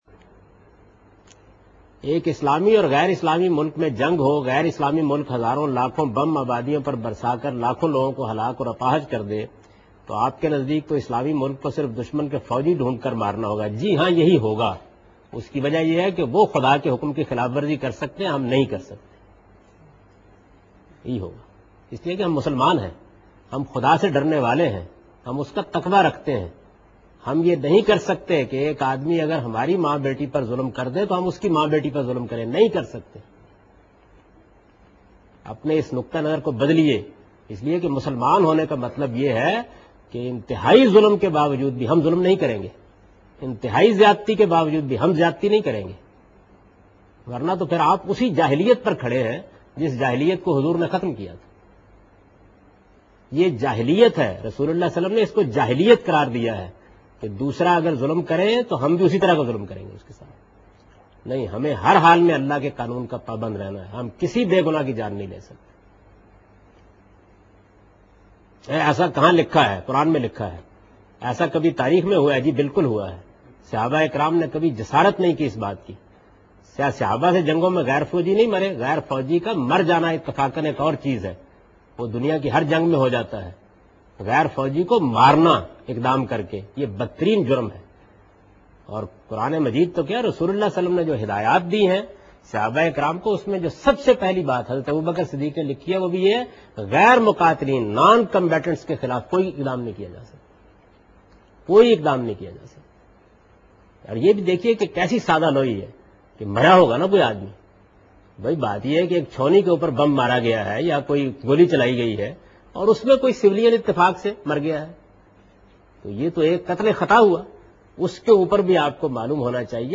Javed Ahmad Ghamidi answering a question regarding "War between Muslim and non-Muslim and killing of Civilians "